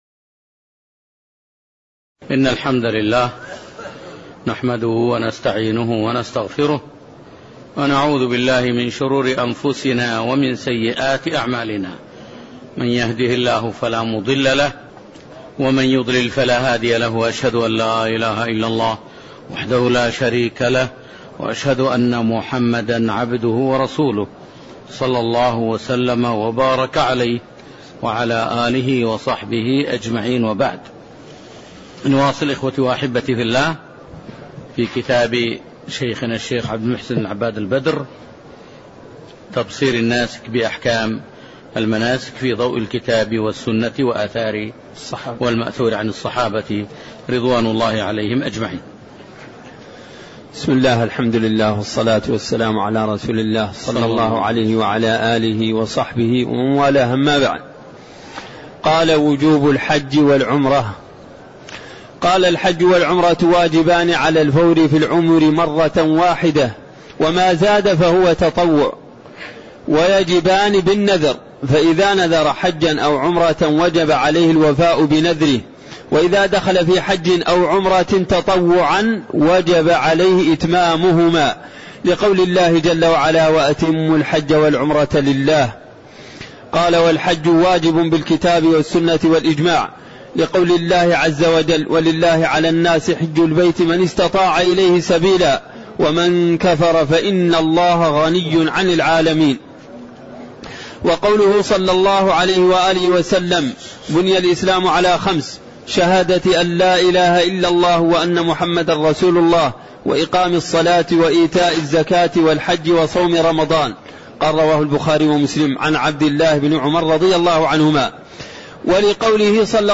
تاريخ النشر ٢١ ذو القعدة ١٤٣٠ هـ المكان: المسجد النبوي الشيخ